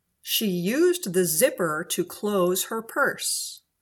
zipper-sentence.mp3